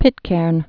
(pĭtkârn)